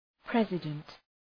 {‘prezıdənt}